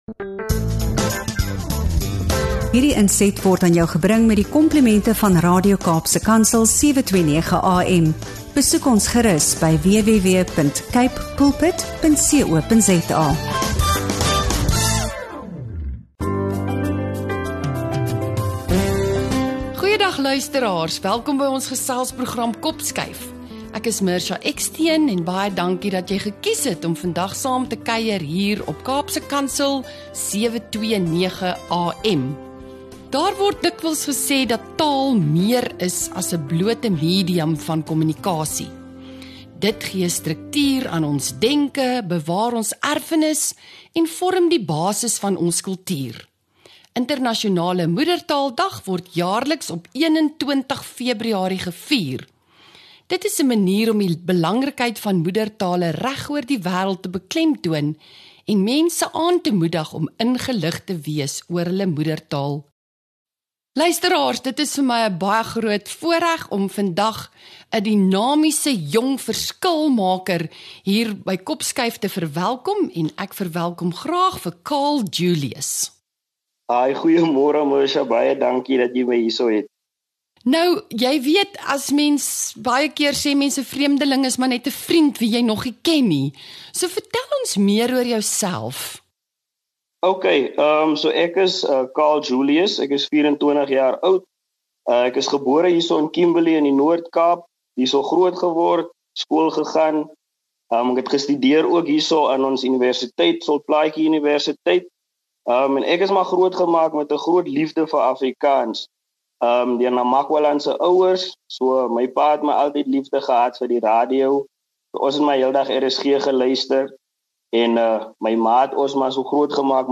n Diep Gesprek oor Taal, Kultuur en die Toekomst van Afrikaans